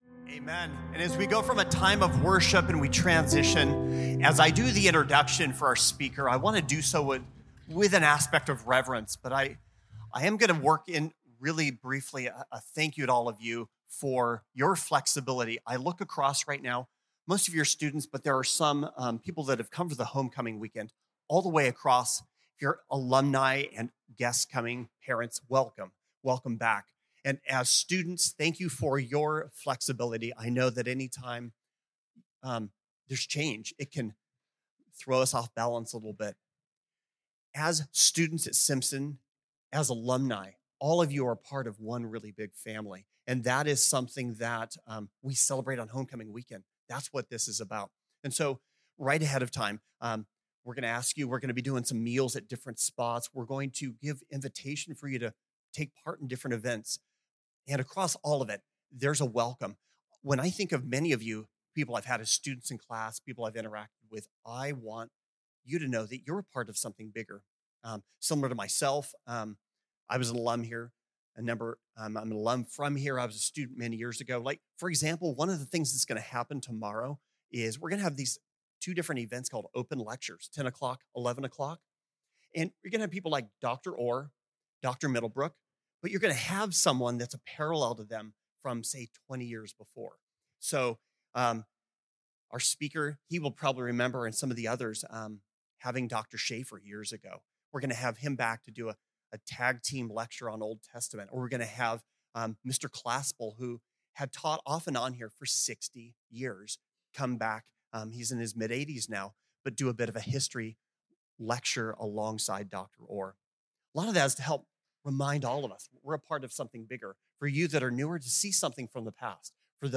This talk was given in chapel on Friday October 18th, 2024.